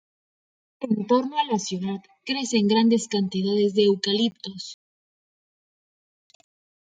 Pronounced as (IPA) /ˈtoɾno/